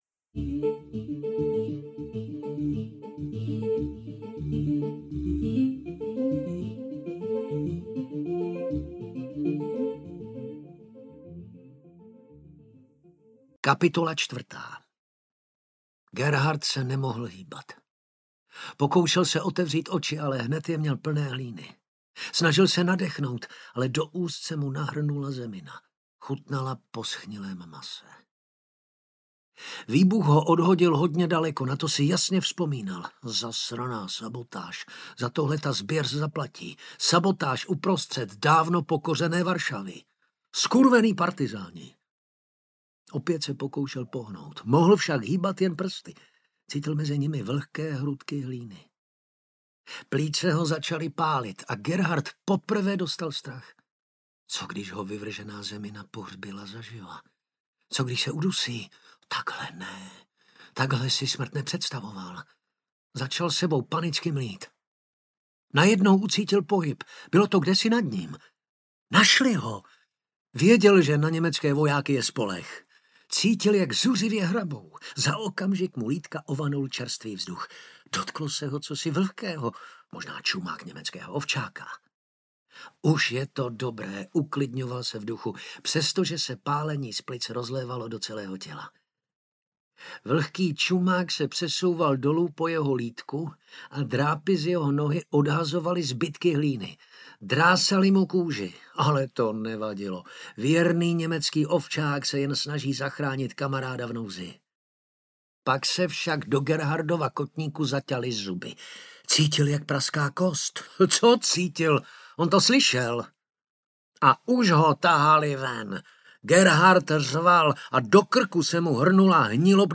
Inferium audiokniha
Ukázka z knihy